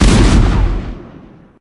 rose_rocket_ulti_explo_01.ogg